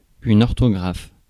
ÄäntäminenFrance : « une orthographe »:
• IPA: [yn‿ɔʁ.tɔ.ɡʁaf]